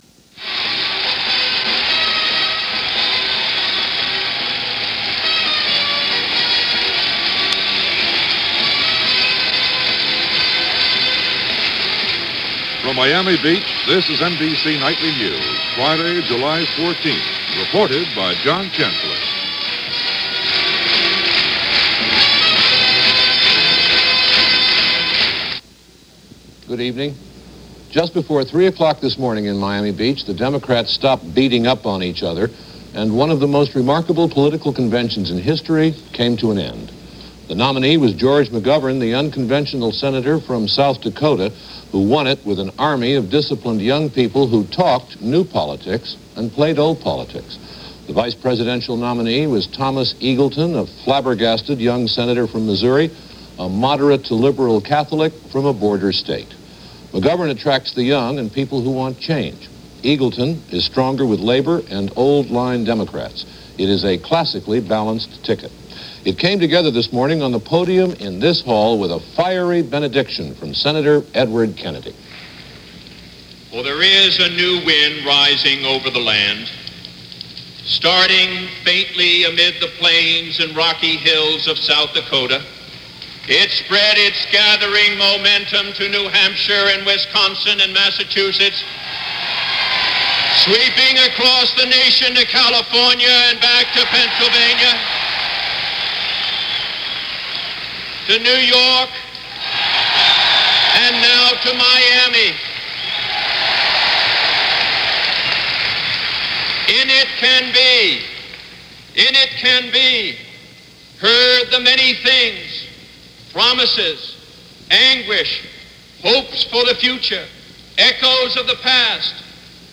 July 14, 1972 – NBC Nightly News (Audio)